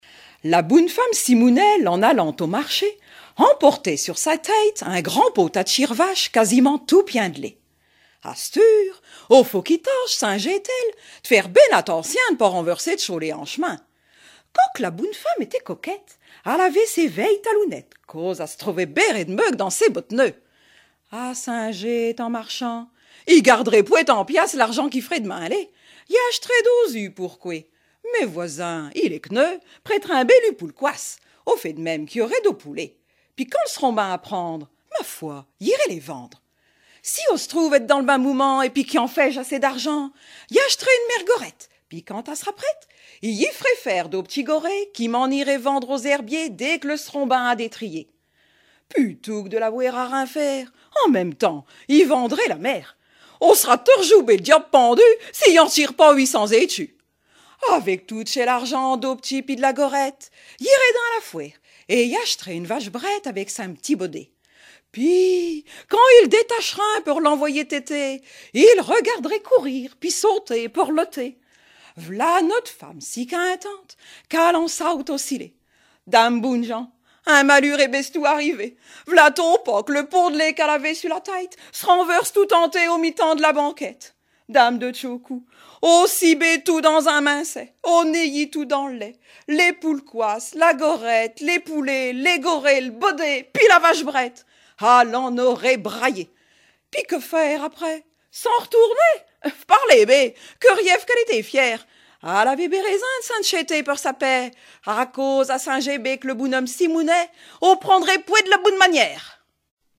Genre fable
Catégorie Récit